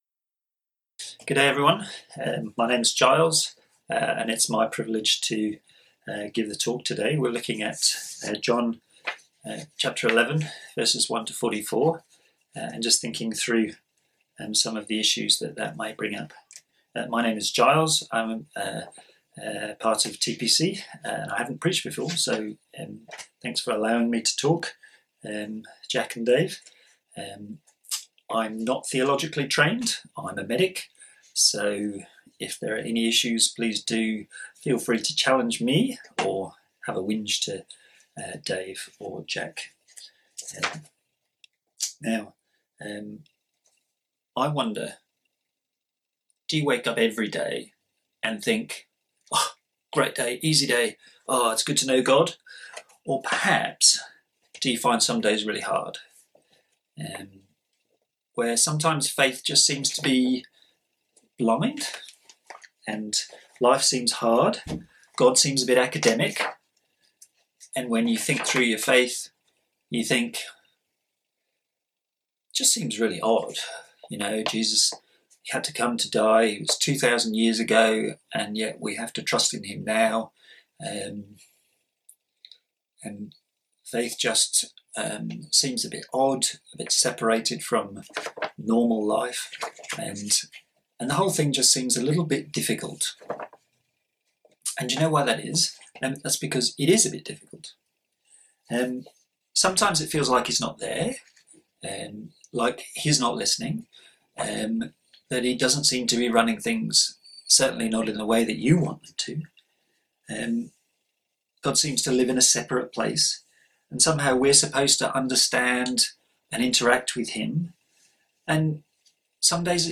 John Passage: John 11:1-44 Service Type: Sunday Morning A sermon on the book of John